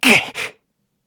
Kibera-Vox_Damage_kr_03.wav